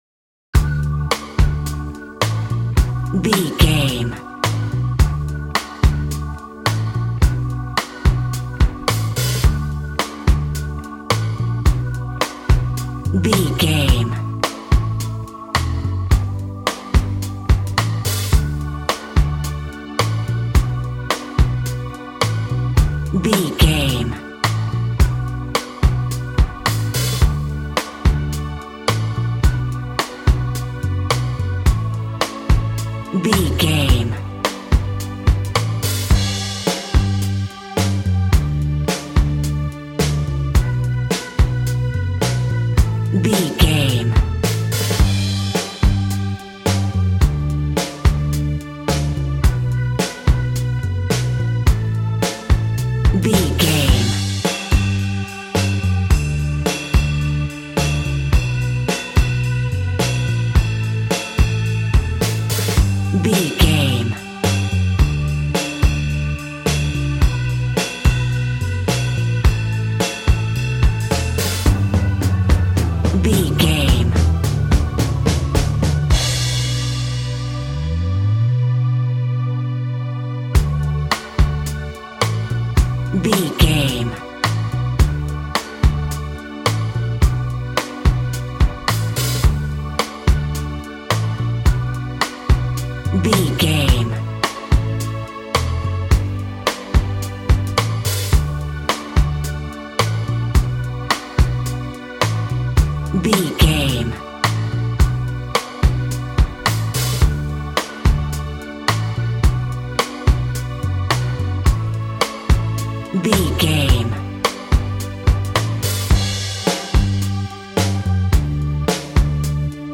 Aeolian/Minor
cheerful/happy
double bass
piano
drums